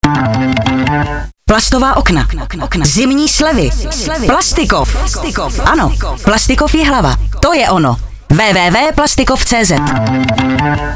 Slogan na radiu Vysočina č.2